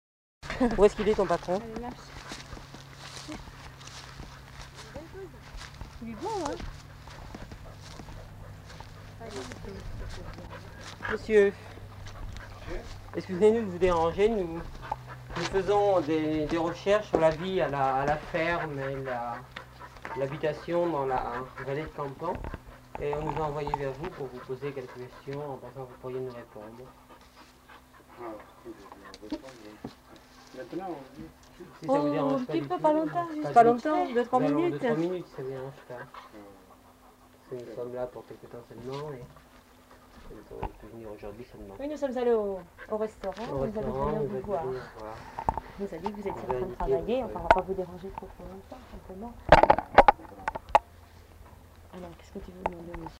Aire culturelle : Bigorre
Lieu : Campan
Genre : parole